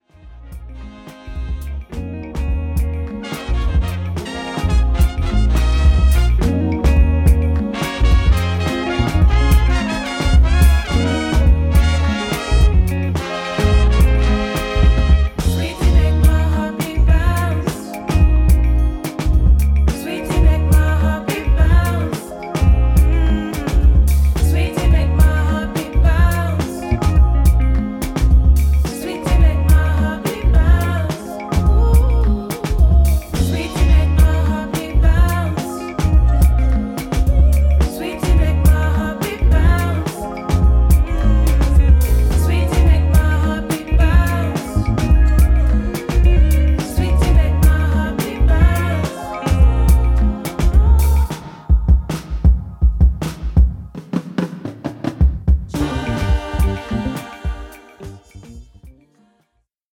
JAZZ / JAZZ FUNK / FUSION